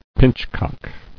[pinch·cock]